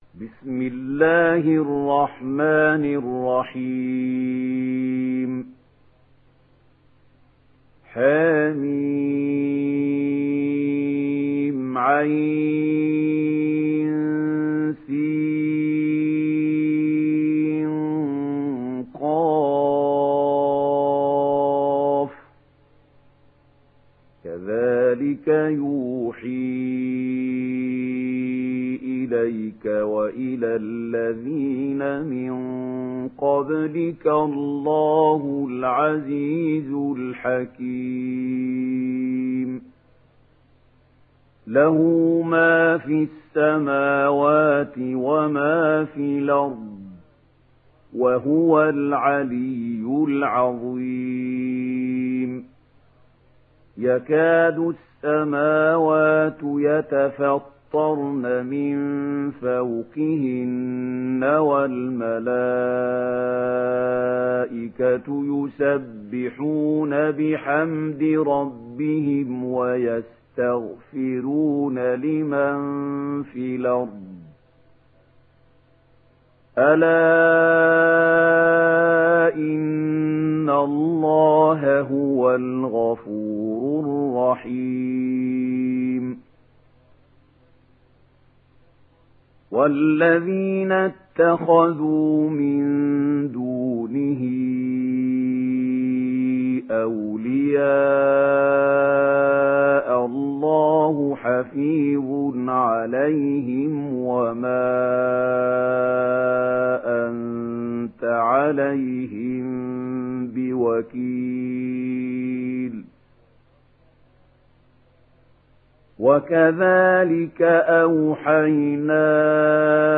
Surah Ash Shura Download mp3 Mahmoud Khalil Al Hussary Riwayat Warsh from Nafi, Download Quran and listen mp3 full direct links